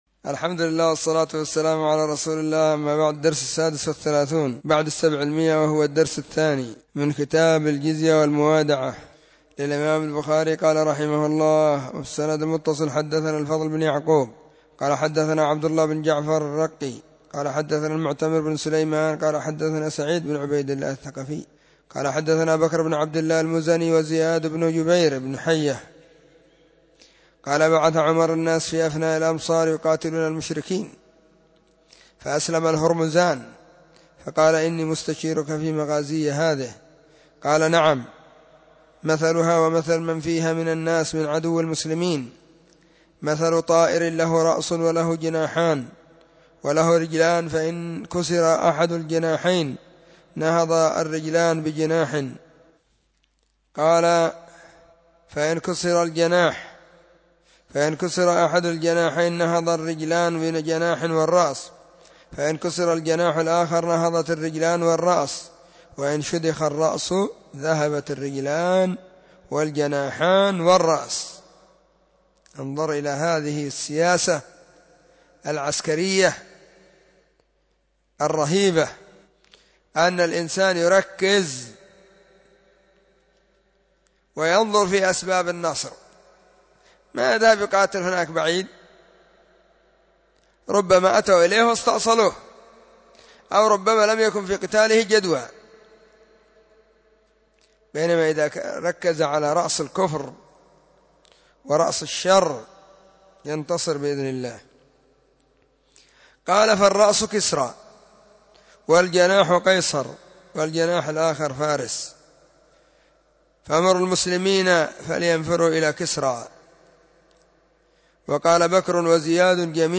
🕐 [بين مغرب وعشاء – الدرس الثاني]